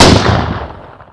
sol_reklam_link sag_reklam_link Warrock Oyun Dosyalar� Ana Sayfa > Sound > Weapons > TYPE89 Dosya Ad� Boyutu Son D�zenleme ..
WR_fire.wav